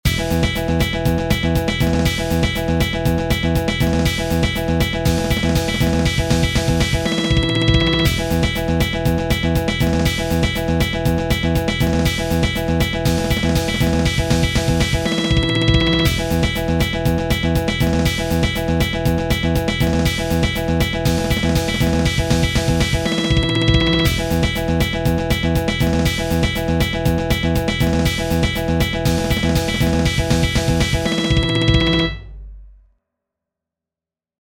Demo of 1edo
1edo_groove.mp3